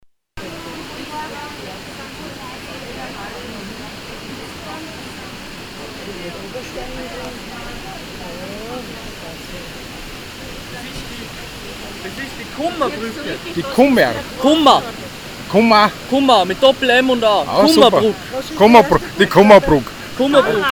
Der Wasserwanderweg in Hittisau. Entlang der Bolgenach hört man die Gewalt des Wassers.